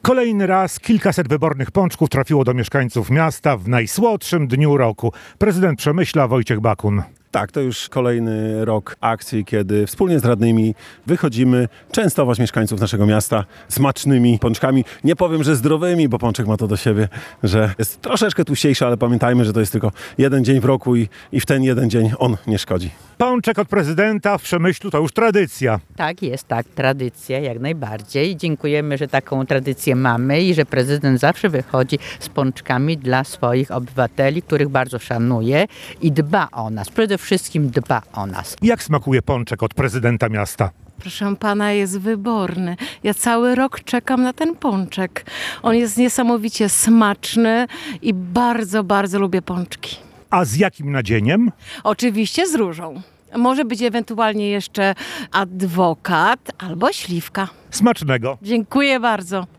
– powiedział Prezydent Przemyśla Wojciech Bakun.